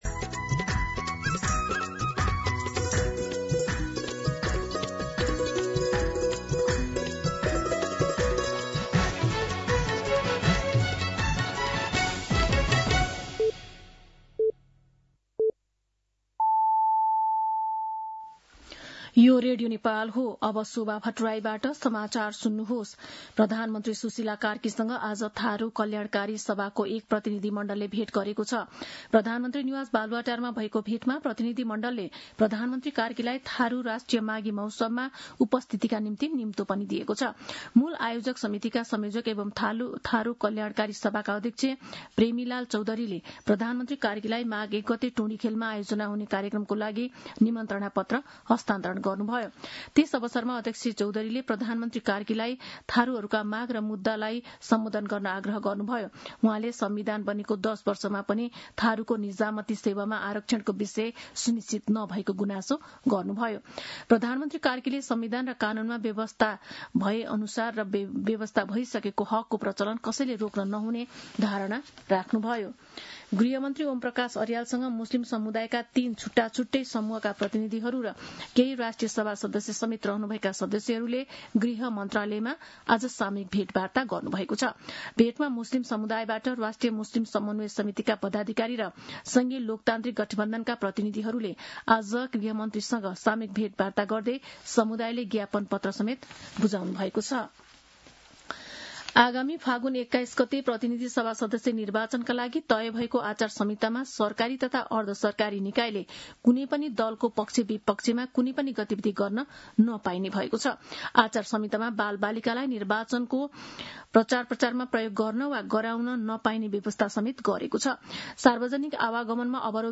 दिउँसो ४ बजेको नेपाली समाचार : २० पुष , २०८२
4pm-Nepali-News.mp3